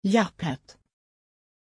Pronunciation of Japheth
pronunciation-japheth-sv.mp3